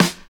60 SNARE 2.wav